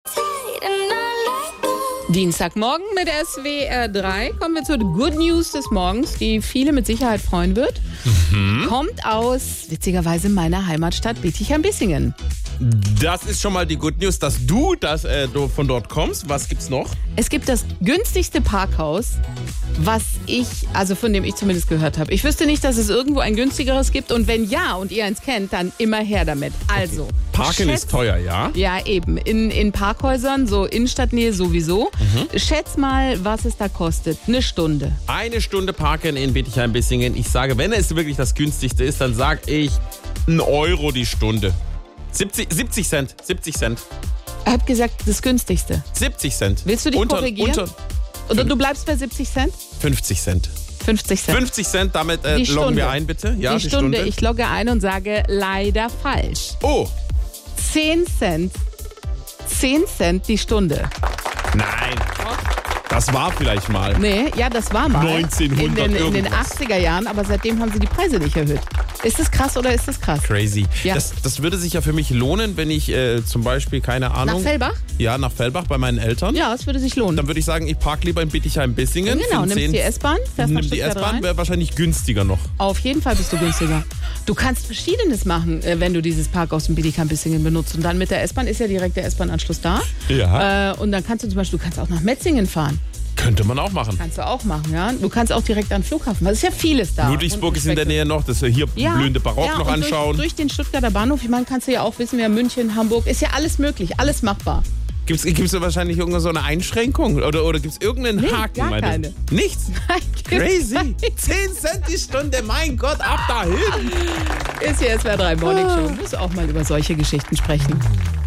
Unsere Moderatoren haben sich in der SWR3 Morningshow auch sehr über dieses Parkhaus gefreut: